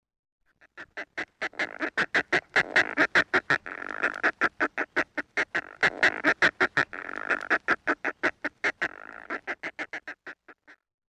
Fulmar - Fulmarus Glacialis
głosy